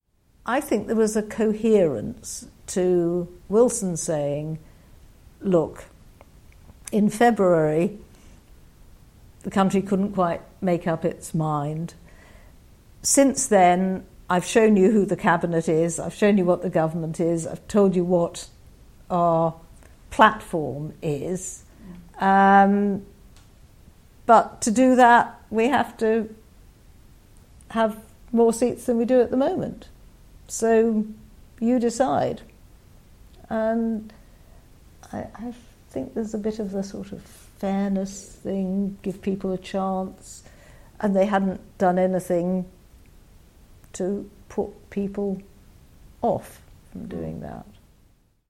In the first in our 2017 election campaign series, we take a look back at the two elections of 1974 through the memories of our oral history project interviewees…